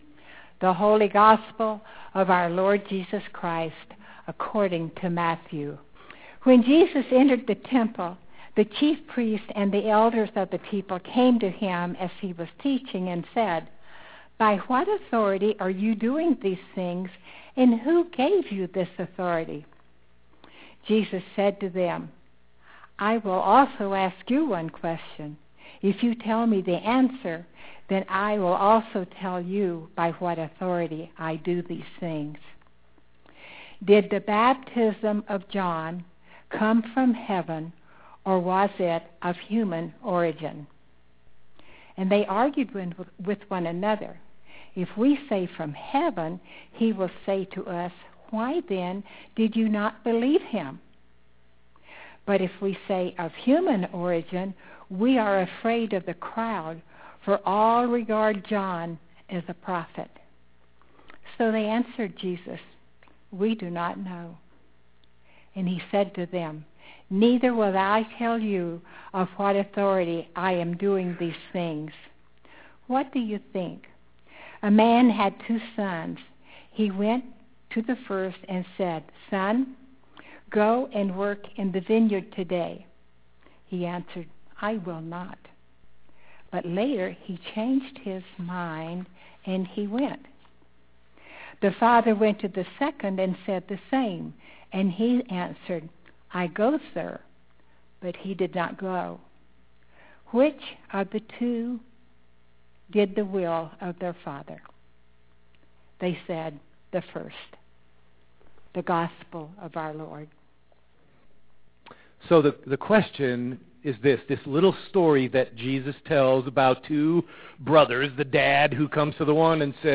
scripture and sermon